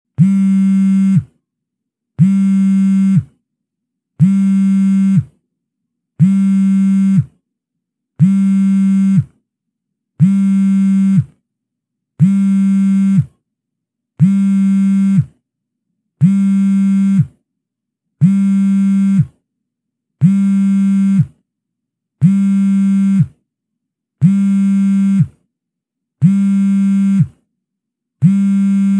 Звуки вибрации телефона, виброзвонка
Длительный вибрирующий звук телефона